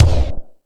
Lotsa Kicks(09).wav